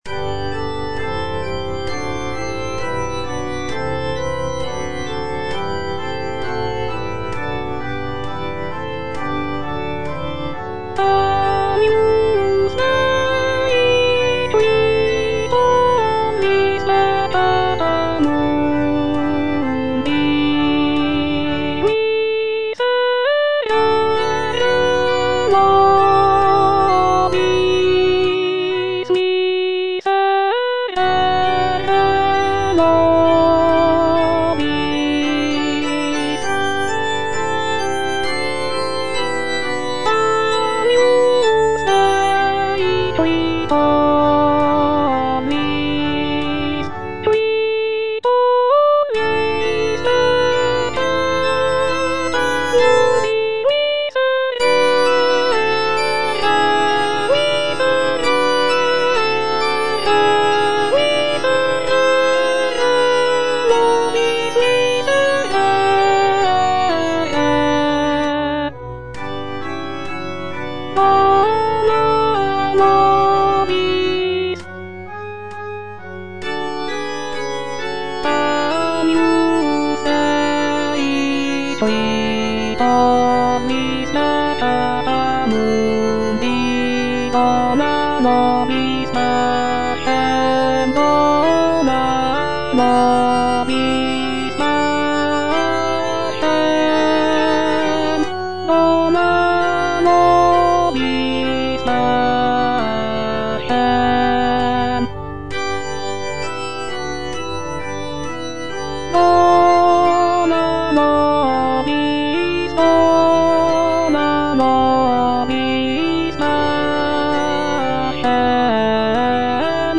G. FAURÉ, A. MESSAGER - MESSE DES PÊCHEURS DE VILLERVILLE Agnus Dei (alto II) (Voice with metronome) Ads stop: auto-stop
The composition is a short and simple mass setting, featuring delicate melodies and lush harmonies.